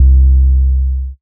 Bass - Bop.wav